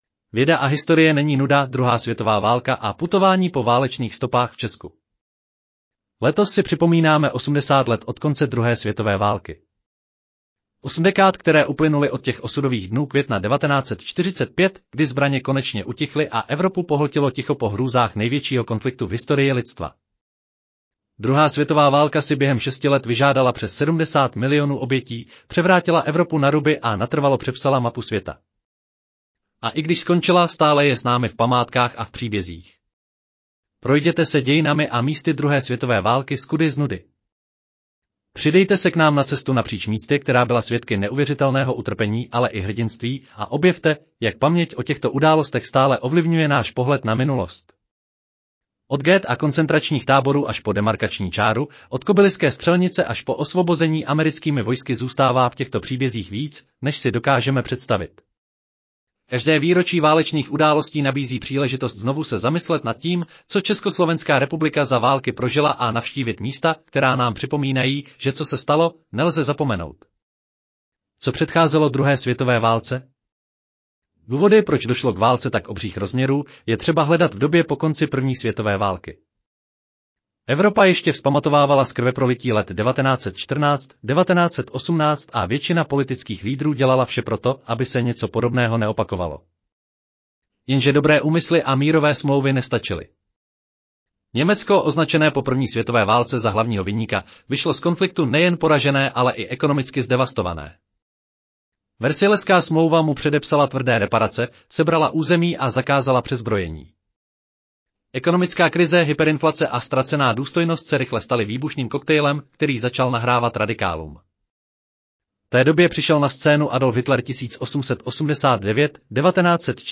Audio verze článku Věda a historie není nuda: Druhá světová válka a putování po válečných stopách v Česku